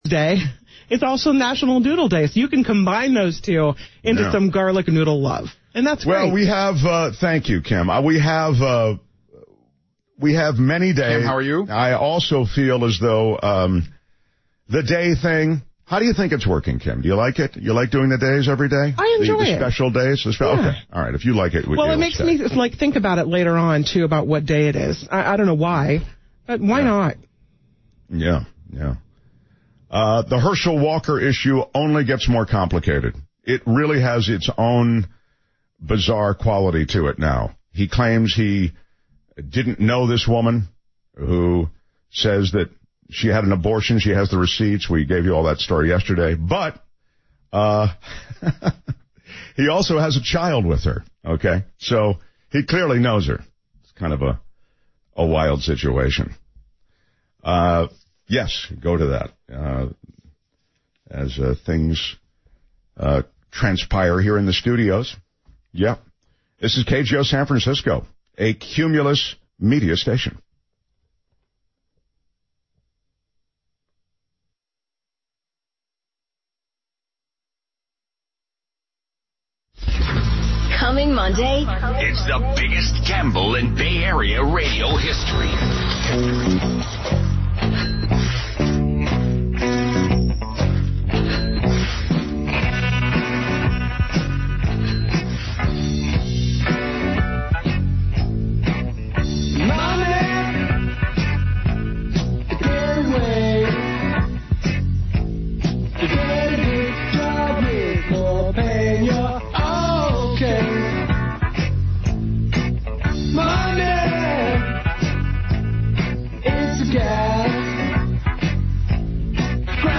810 KGO San Francisco Sign-Off
Previous Format: News/Talk